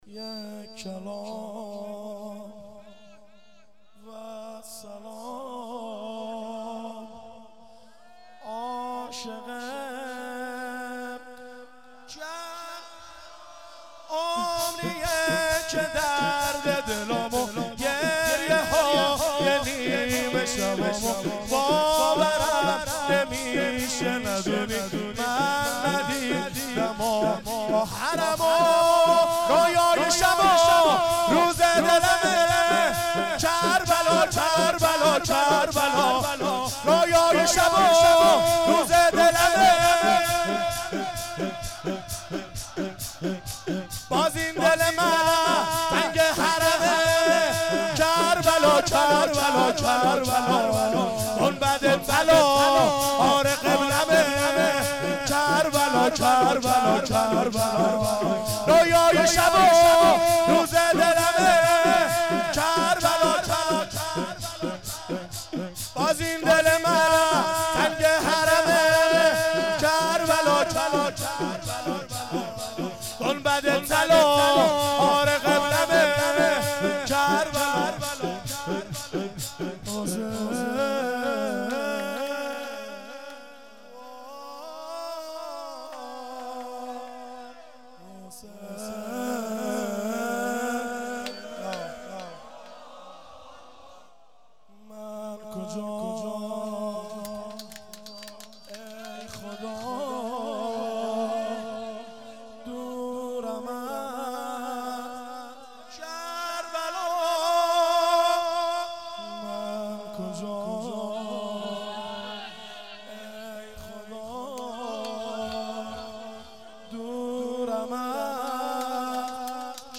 شور،یک کلام والسلام عاشق کربلام